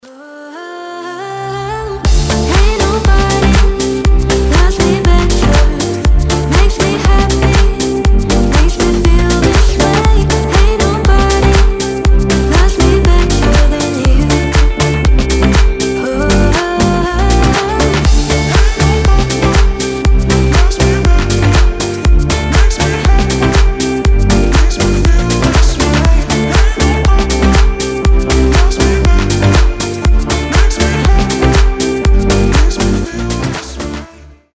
• Качество: 320, Stereo
deep house
женский голос
Cover
теплые
Chill